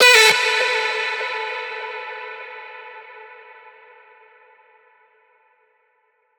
VR_vox_hit_mmhmm2_E.wav